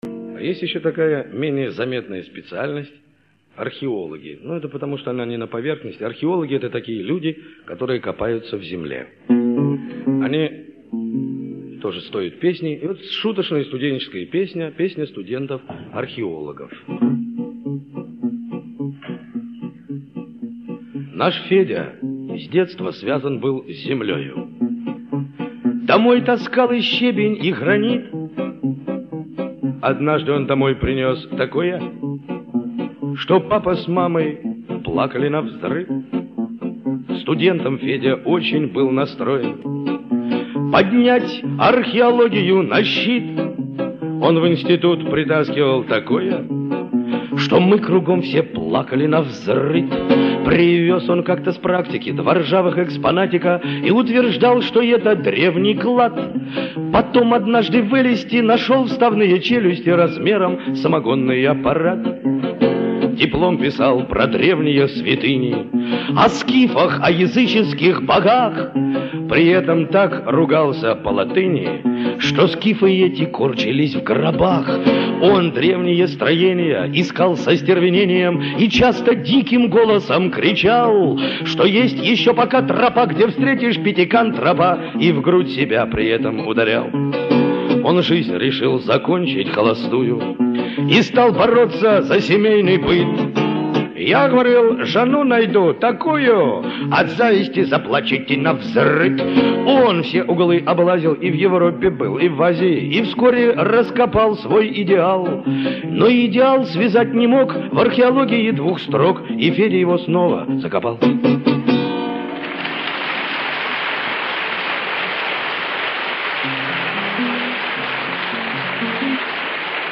А эта совсем не коряво звучит